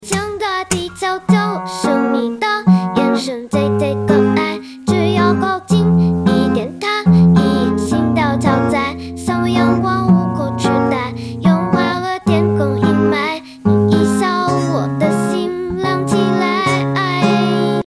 吉伊卡哇（chiikawa）小八 RVC模型
唱歌